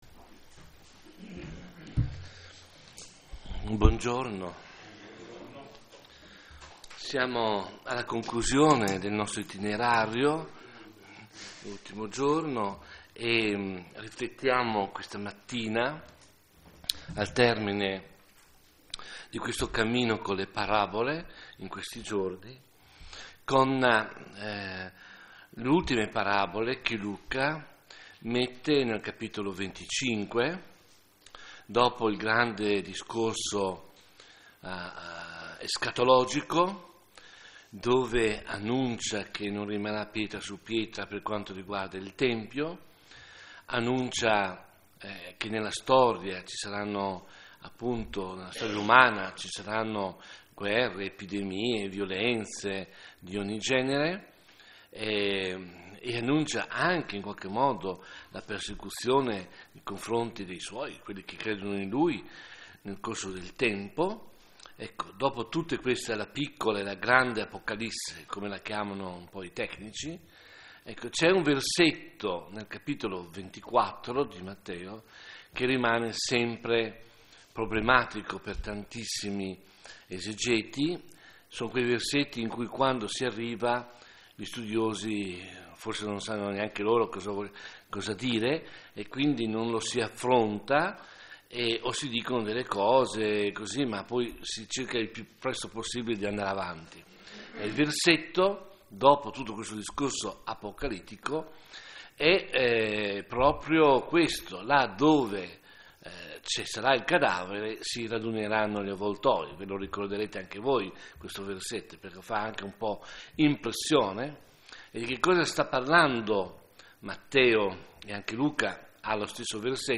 9a MEDITAZIONE
Serie: Meditazione